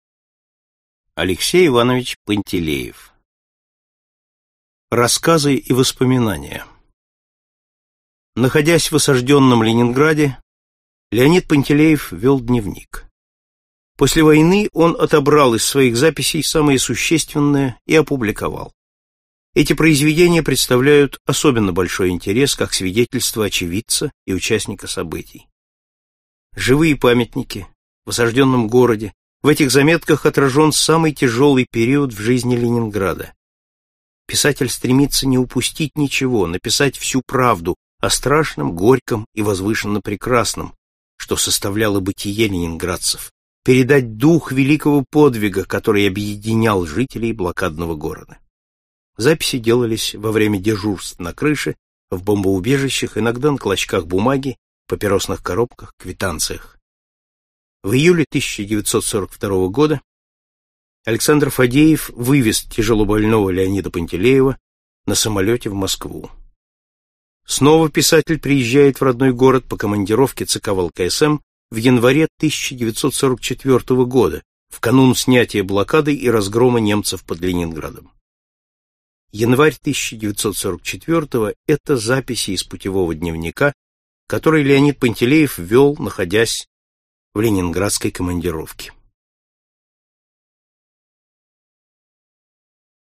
Аудиокнига В осажденном городе | Библиотека аудиокниг